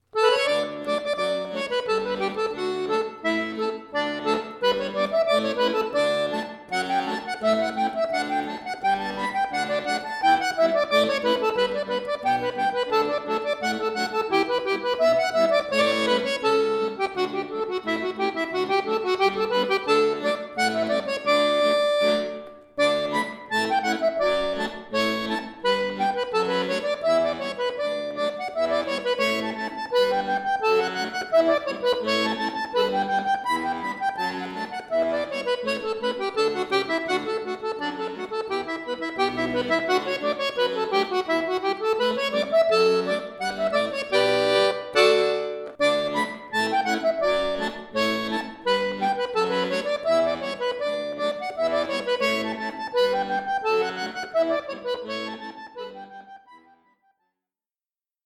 Folk, Irisch, Klassisch